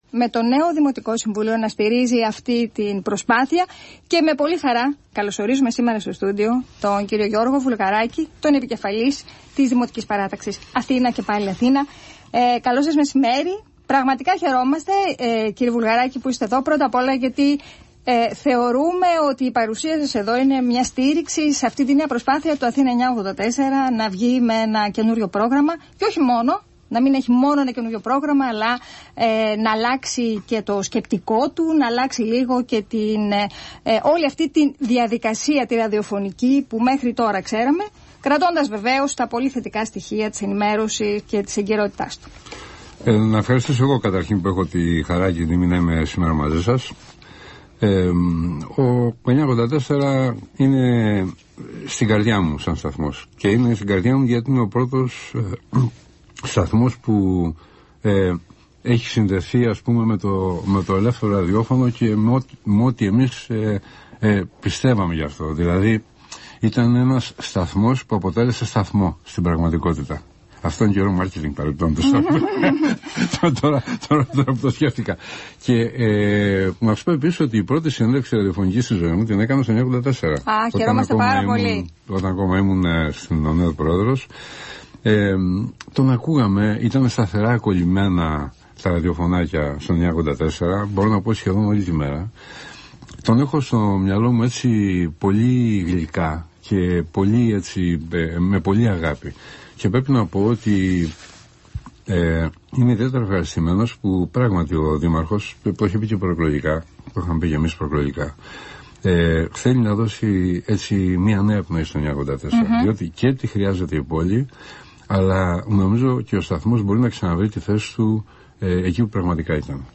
H καθημερινότητα του πολίτη, η συστηματική δουλειά στον καθαρισμό την αποκομιδή και τον καλλωπισμό της Αθήνας, ώστε να αναδειχθεί το φωτεινό της πρόσωπο- όπως της αξίζει- προκύπτει ως κεντρικό σημείο από τη συνέντευξη του Γιώργου Βουλγαράκη στον Αθήνα 9.84.